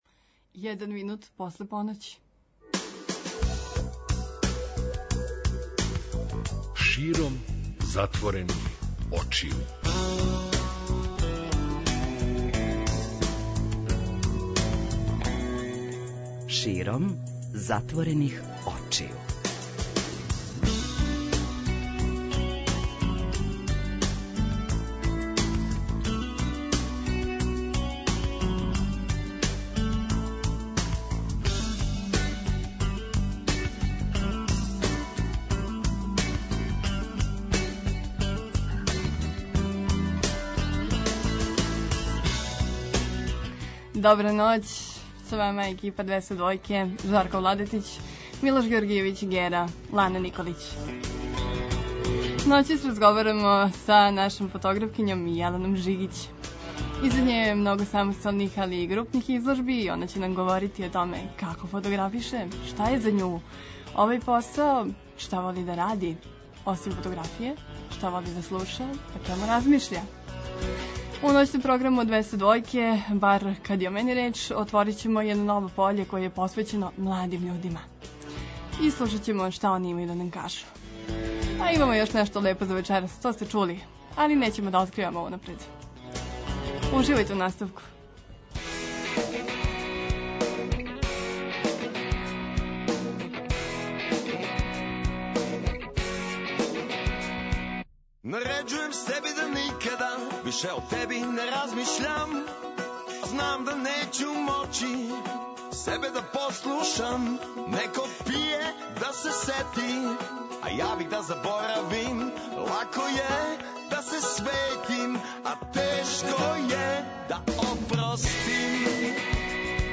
Ноћни програм Радио Београда 202 простор је за све младе који раде креативне и добре ствари!